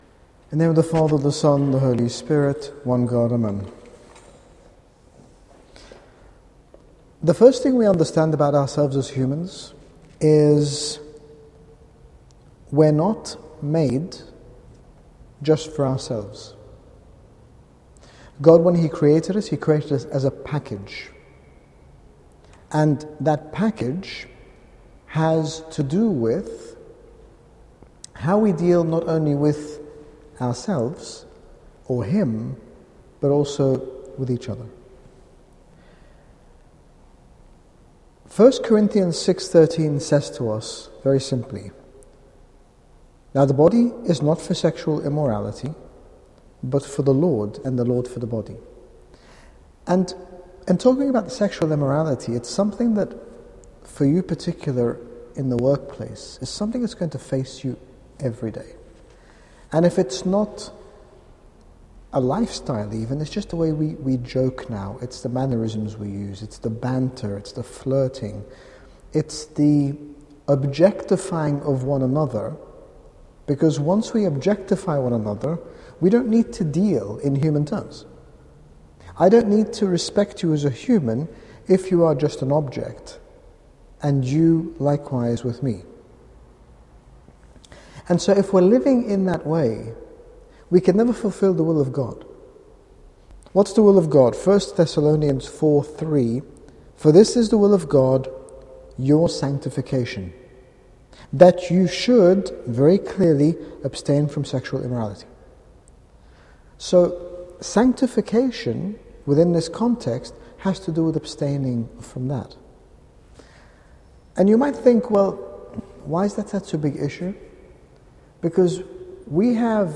His Grace Bishop Angaelos, General Bishop of the Coptic Orthodox Church in the United Kingdom speaks about purity of the person within. This talk was delivered at the Grapevine Fellowship Meeting in London on 13 August 2014.